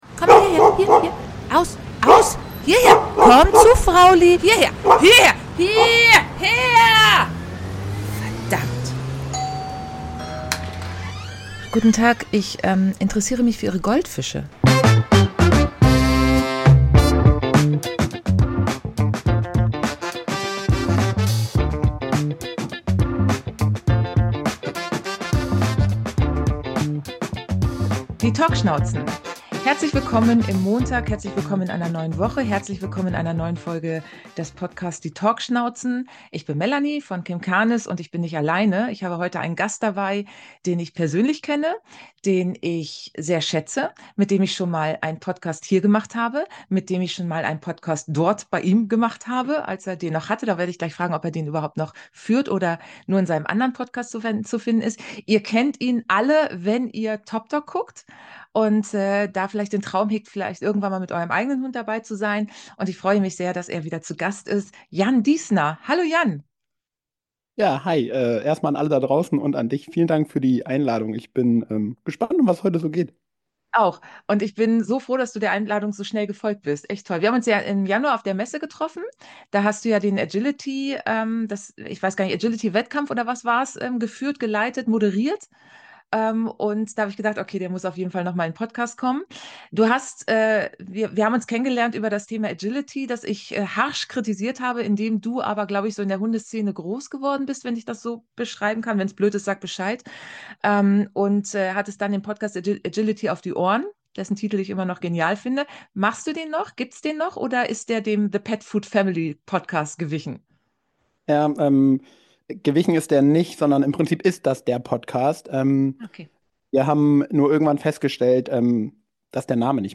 Was für ein interessantes Gespräch zwischen zwei Menschen, die ähnliche Vorstellungen von Hundeleben, Miteinander, Wettkampf, Alltag, Beschäftigung und vielem Drumherum haben.